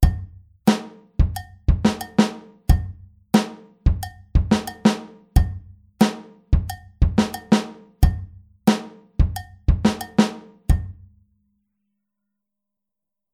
Rechte Hand auf Kopfbecken (bell/head) oder Kuhglocke (cowbell)
Bei der 4tel-Variante spielt die rechte Hand nicht mehr auf dem HiHat sondern wegen des Punches auf dem Kopfbecken (bell/head aber nicht mit der Kuppe) oder der Kuhglocke (cowbell).